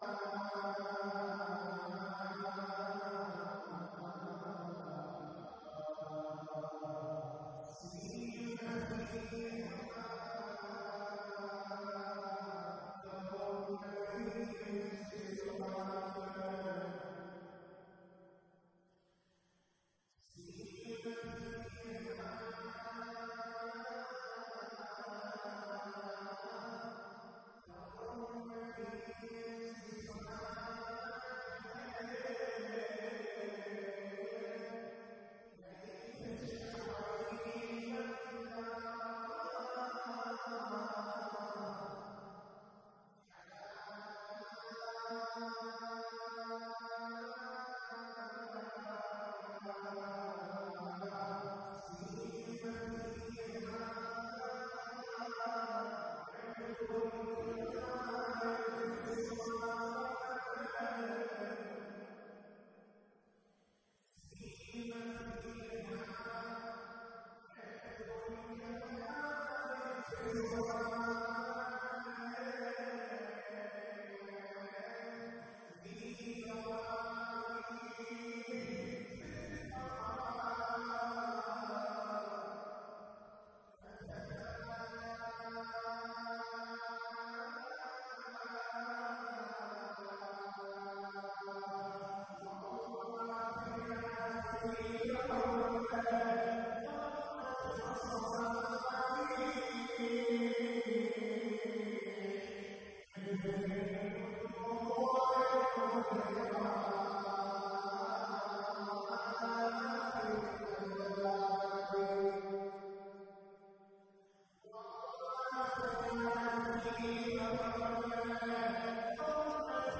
تاريخ المحاضرة: 07/09/1437 نقاط البحث: تاريخ الأسرة الطالبيّة لماذا سمّى عبد المطلب (ع) أبا طالب (ع) بعبد مناف؟